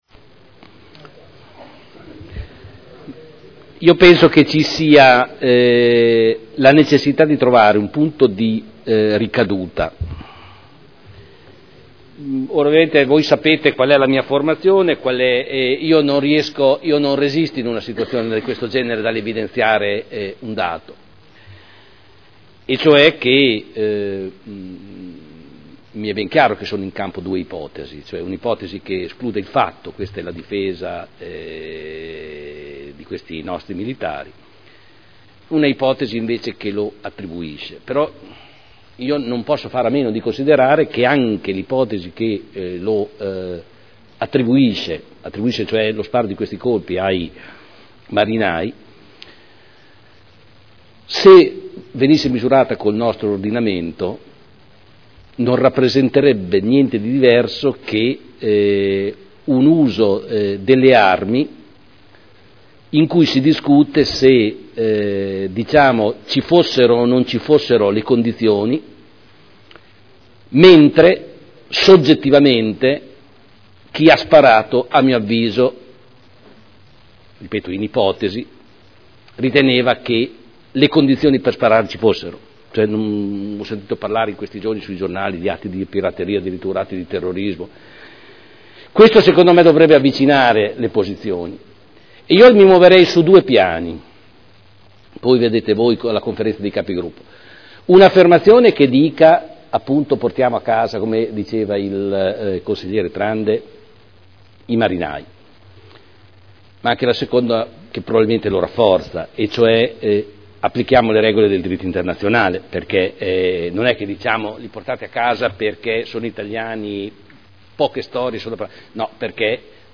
Giorgio Pighi — Sito Audio Consiglio Comunale